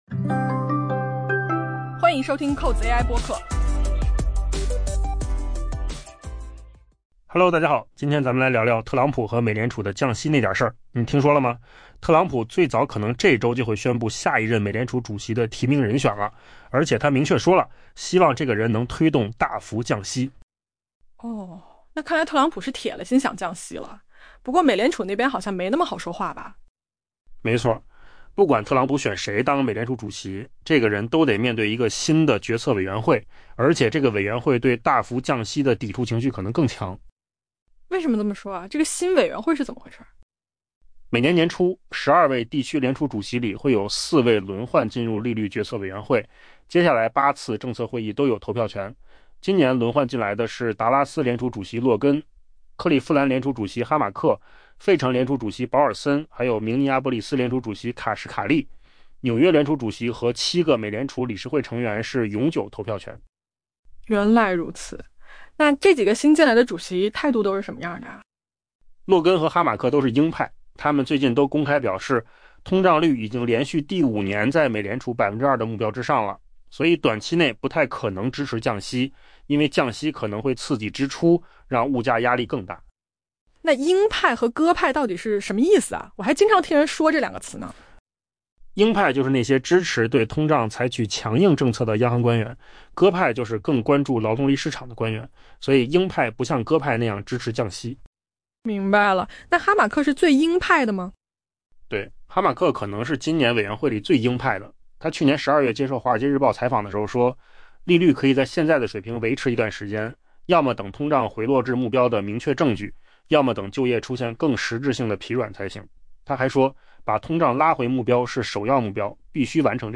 AI 播客：换个方式听新闻 下载 mp3 音频由扣子空间生成 美国总统特朗普最早可能在本周宣布下一任美联储主席的提名人选，他已发出信号，要求获提名者必须推动大幅降息。